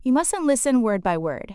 「mustn’t」は must notの短縮系で「マスン」と、